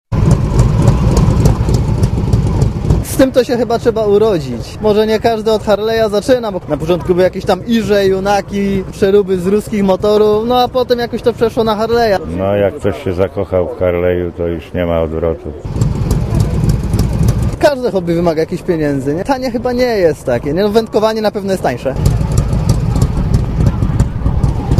Mówią uczestnicy zlotu
motocyklisci.mp3